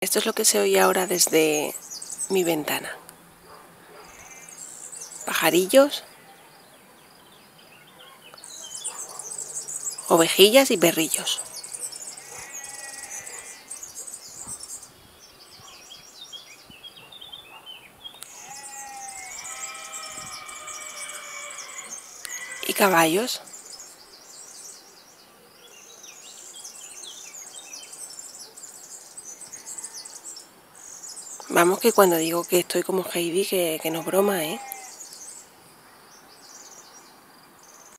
Después de mi periplo de 10 años por Madrid, hace un año y por primera vez, estoy viviendo en una montaña como a un kilómetro de la civilización, en un pueblo que se llama «Valle de Turón» de la cuenca minera.
Este audio lo grabé este verano un día cualquiera desde la ventana de mi habitación.